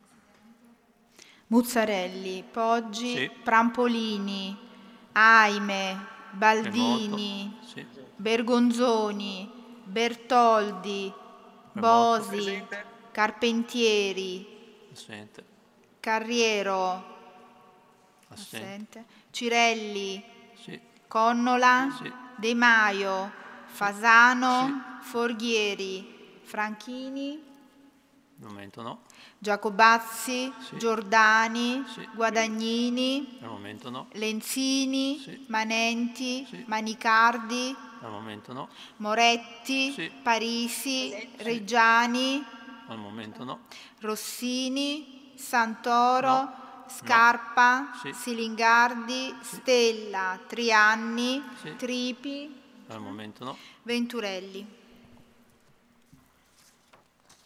Seduta del 23/07/2020 Appello.
Segretaria